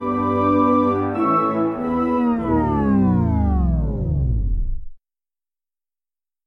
На этой странице собрана коллекция звуковых эффектов, символизирующих неудачу, провал и комичные поражения.
Фейл Вариант 2